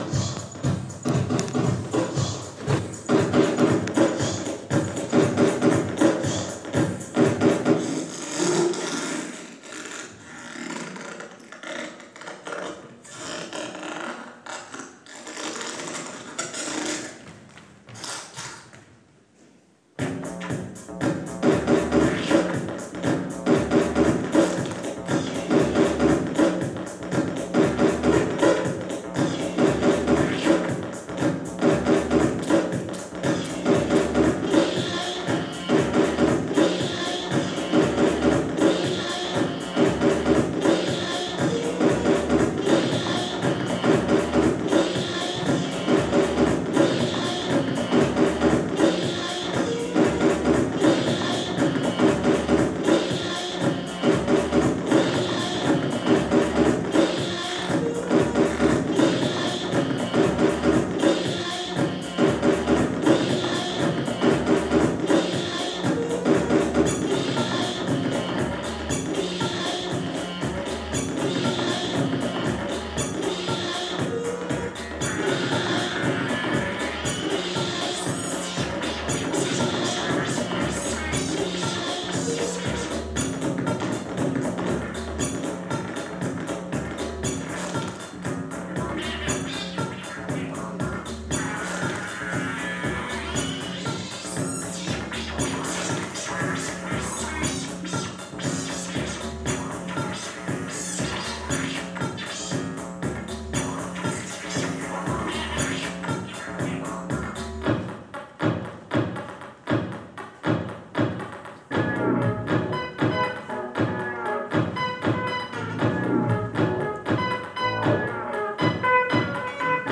Contemporary dance at Trinity Laban